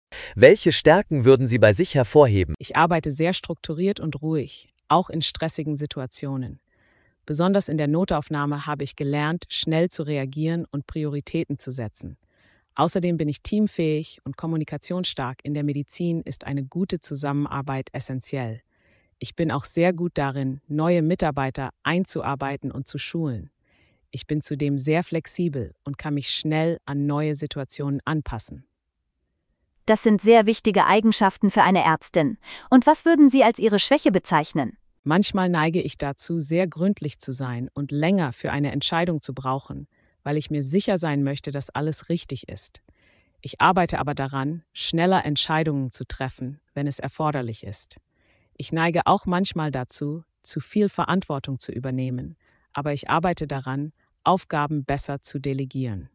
AudioBook - Vorstellungsgespräch Ärzte
Komplettes Gespräch – Realistische Fragen und Antworten speziell für Ärztinnen und Ärzte